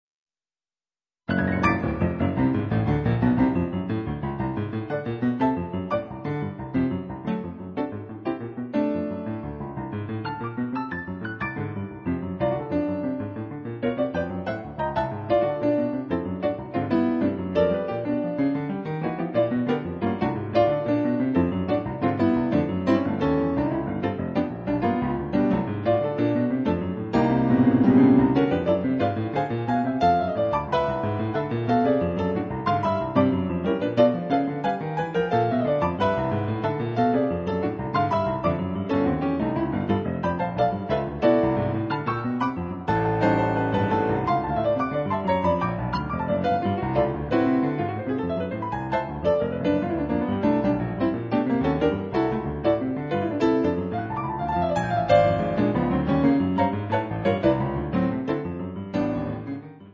pianoforte
dal suono avvolgente e trascinante.